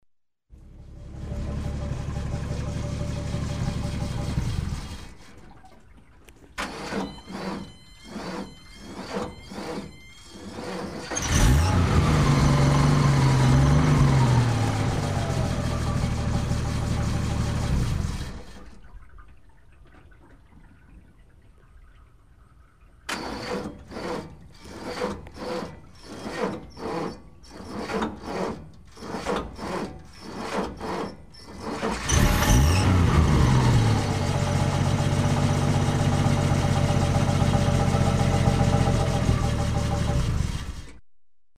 Дизельный грузовик: остановка двигателя, попытки пуска
Тут вы можете прослушать онлайн и скачать бесплатно аудио запись из категории «Авто, транспорт, машины».